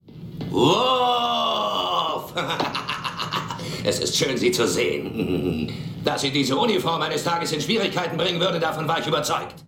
begrüßt als Gowron seinen Freund Worf